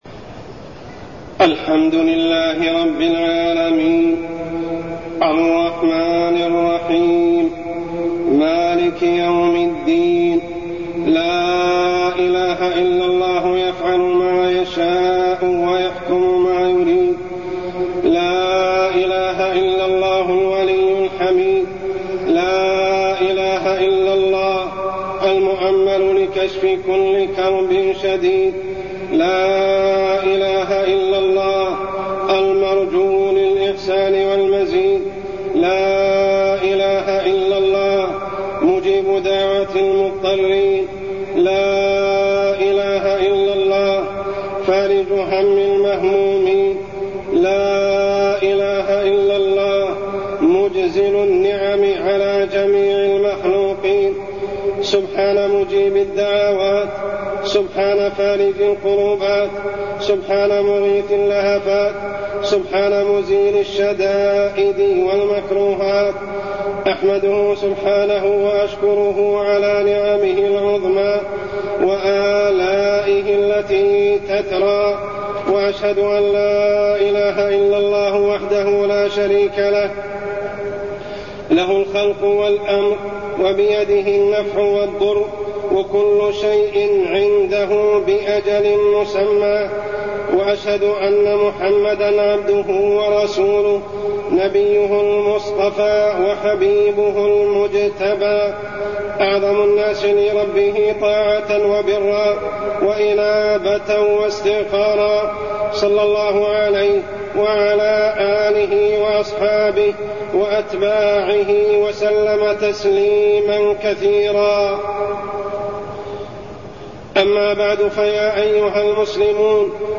تاريخ النشر ٢١ ذو الحجة ١٤٢٠ هـ المكان: المسجد الحرام الشيخ: عمر السبيل عمر السبيل الإعراض عن طاعة الله